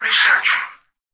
"Researching" excerpt of the reversed speech found in the Halo 3 Terminals.
H3_tvox_hex4_researching_(unreversed).mp3